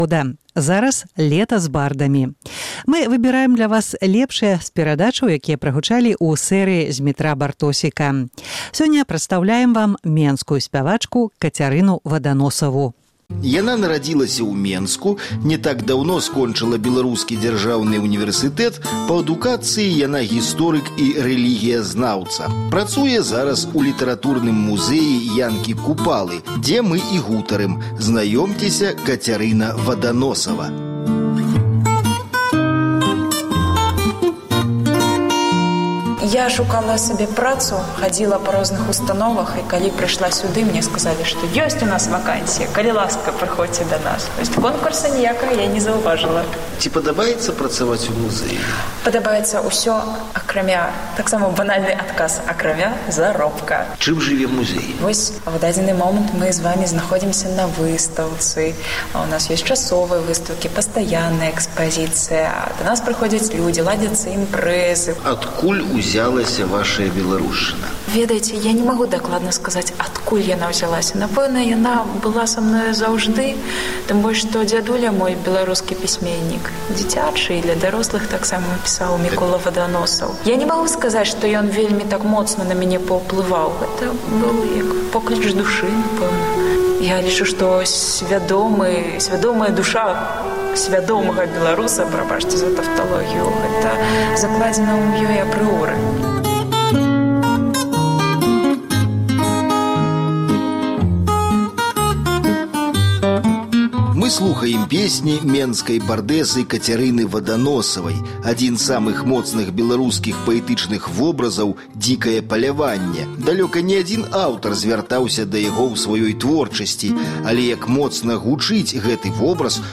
«Барды Свабоды» — сэрыя перадач, якія выходзілі на «Свабодзе» на працягу 2011 году. Дзясяткі аўтараў — ад пачынальнікаў і клясыкаў жанру да пачаткоўцаў і прадаўжальнікаў — разважаюць пра музыку, пра Радзіму, пра прызначэньне творцы і сакрэты сваіх талентаў.